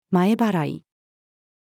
前払い-female.mp3